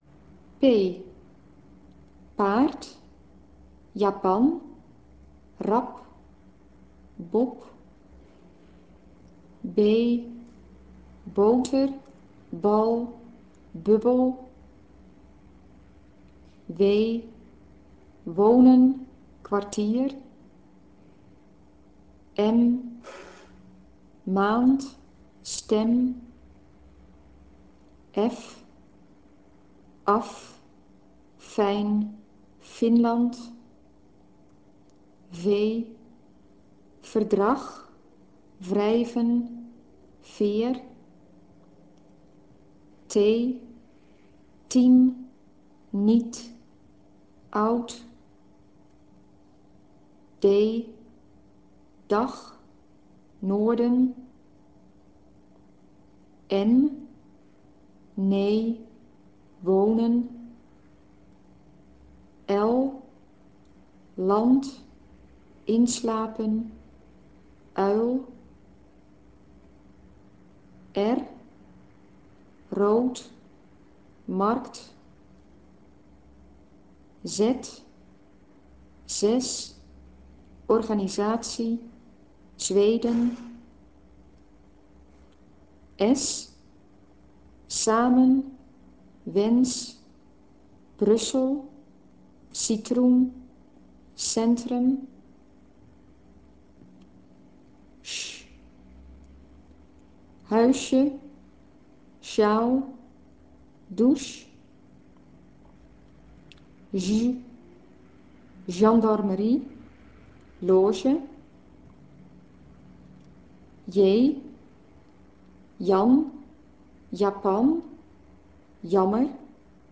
medeklinkers en
medeklinker.rm